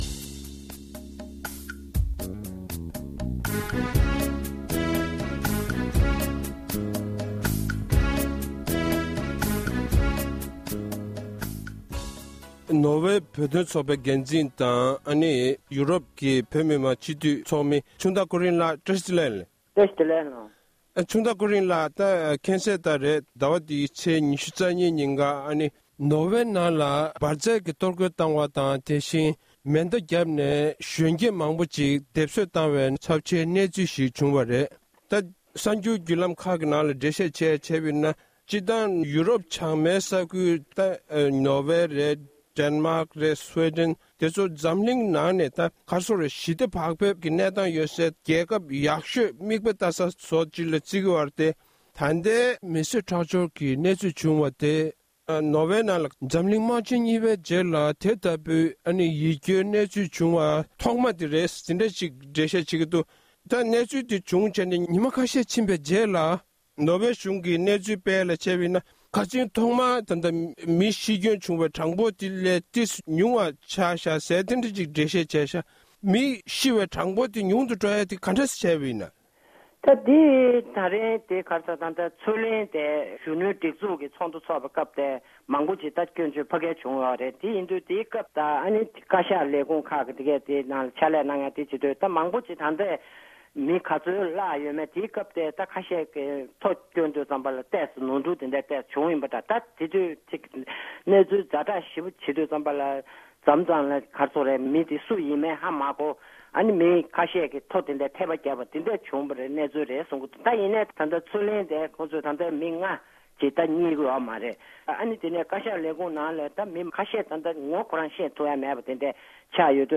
བཀའ་འདྲི་ཞུས་པ